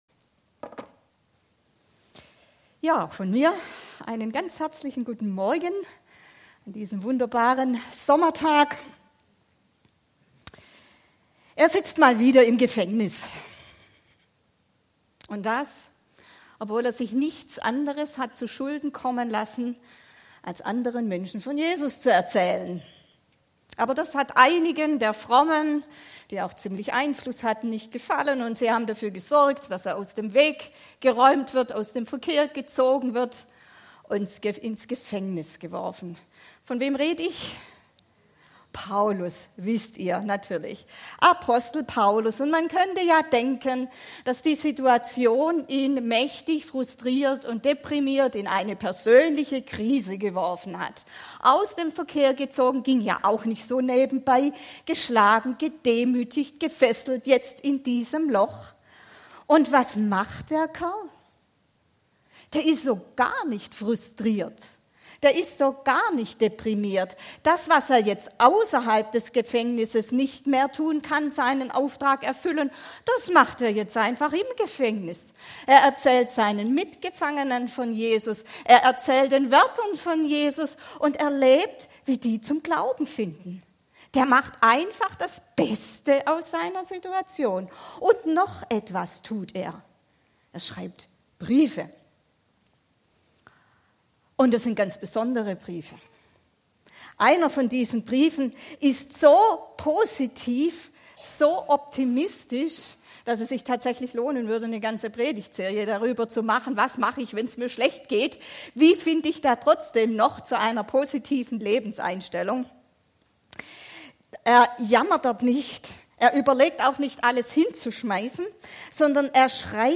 Passage: Philipper 3, 12-15 Dienstart: Gottesdienst